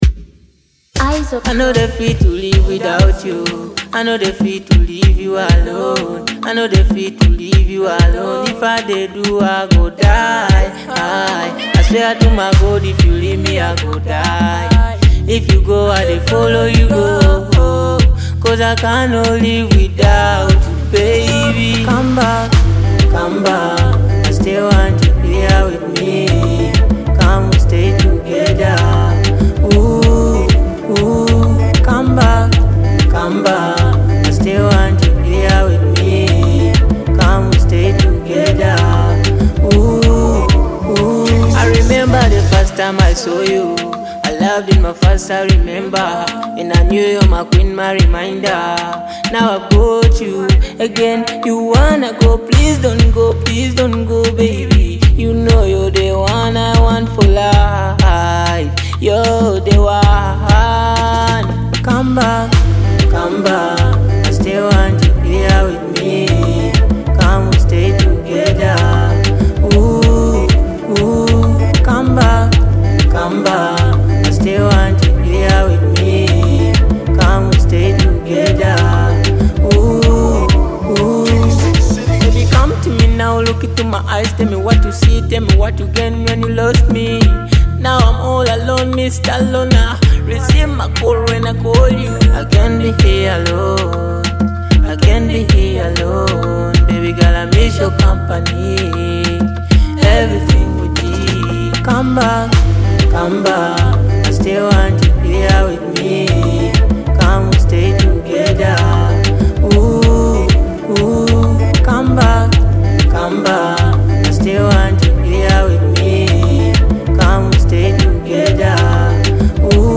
a passionate and emotional track